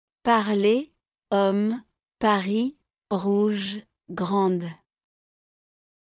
2. H-ը երբեք չի արտասանվում։
4. R-ը արտասանվում է կոկորդից։
Վերոնշյալ բառերի արտասանությունները ՝